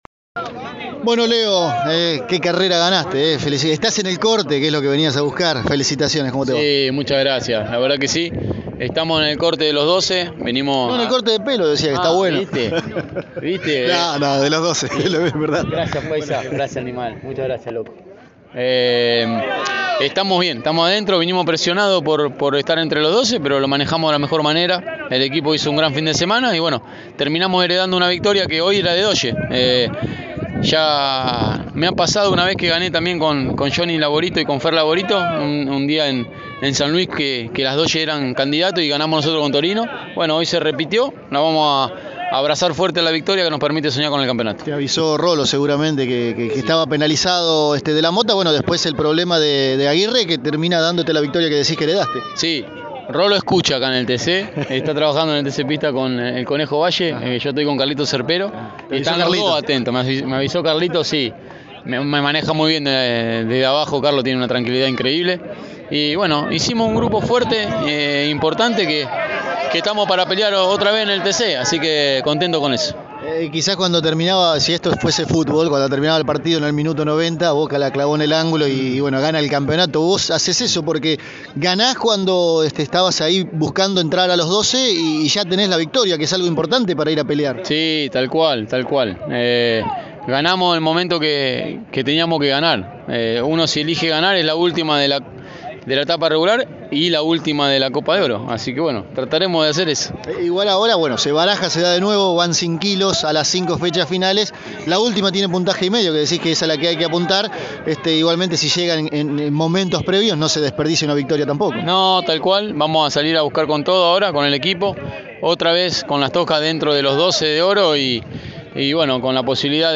En dialogo con Pole Position, esto nos decía Leonel Pernía: